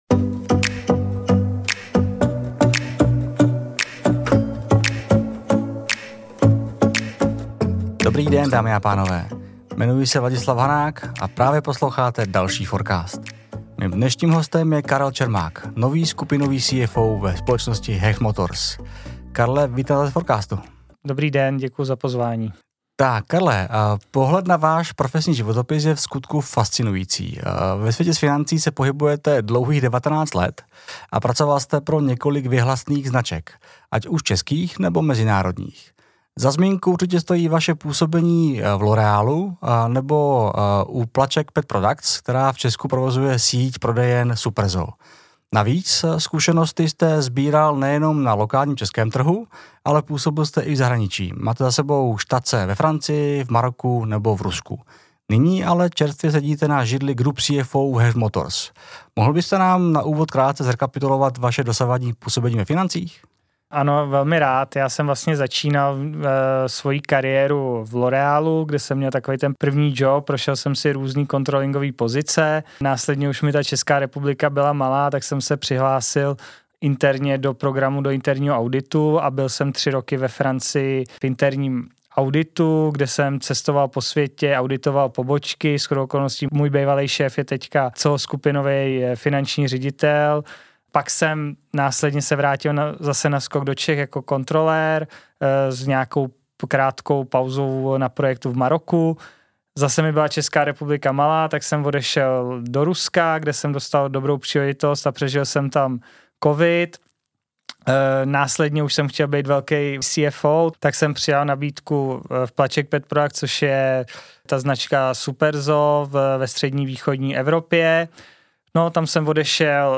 💪 V našem rozhovoru jsme otevřeli témata, která dnes řeší každý CFO i finanční tým. Mluvili jsme o nástrojích, datech, o roli CFO v byznysu – ale i o konkrétních f*ck-upech, které vás možná až příliš dobře pobaví (nebo připomenou vlastní zkušenost).